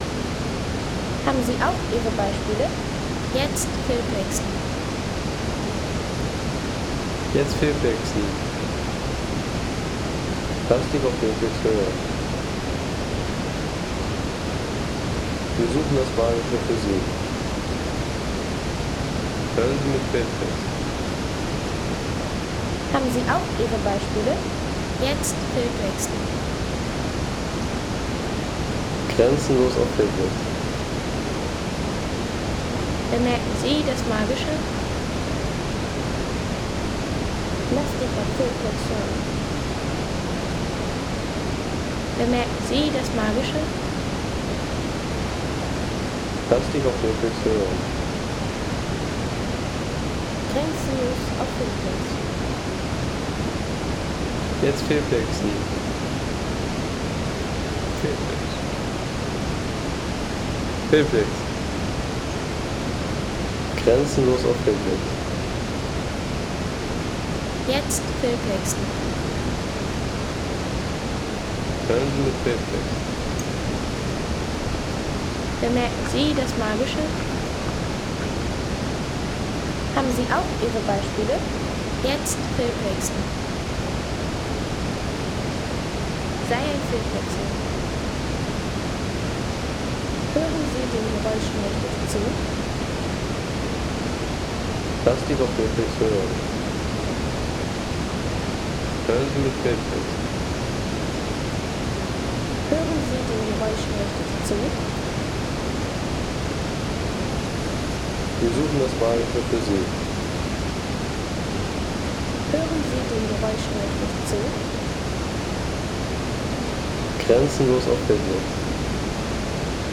Kategorien: Landschaft - Wasserfälle